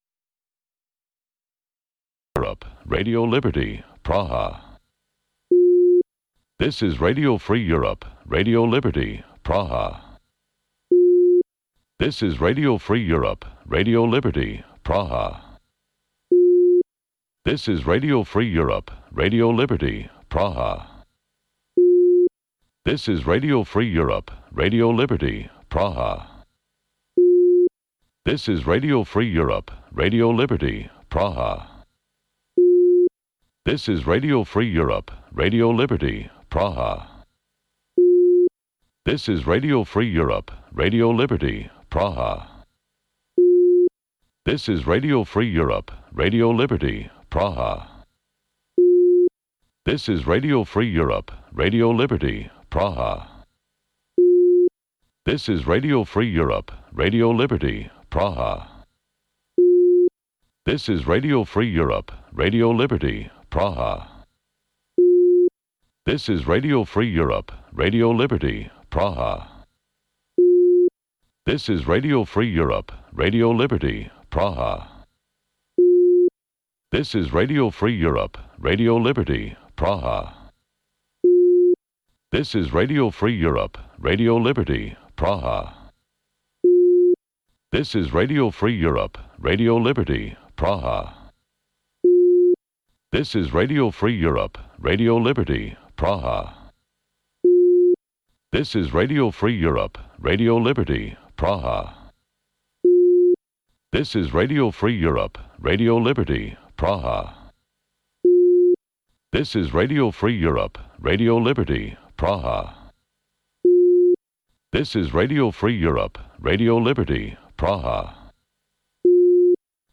Бул үналгы берүү ар күнү Бишкек убакыты боюнча саат 19:00дан 20:00га чейин обого түз чыгат.